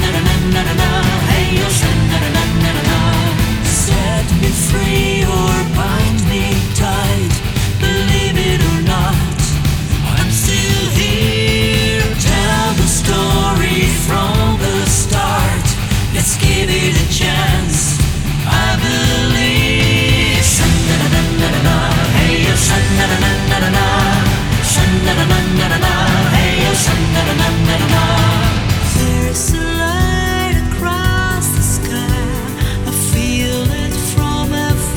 Folk Pop
Жанр: Поп музыка / Фолк